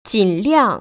[jĭnliàng]